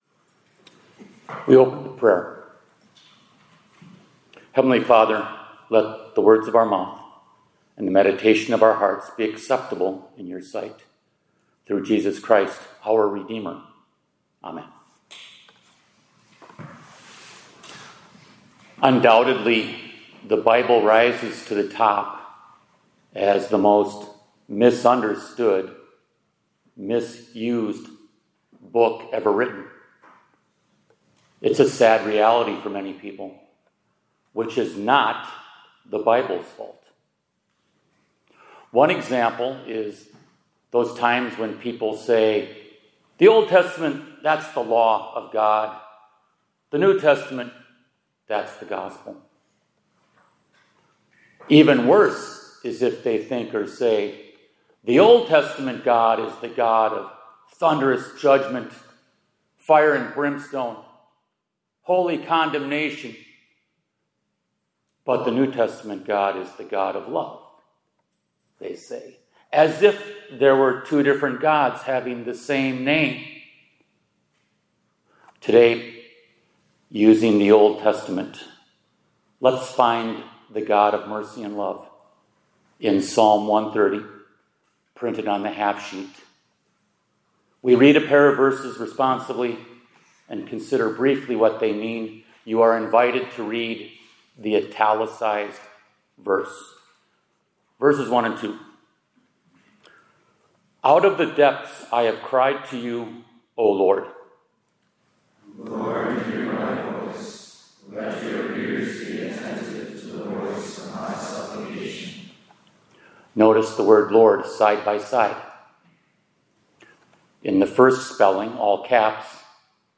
2025-10-06 ILC Chapel — What a Great God You…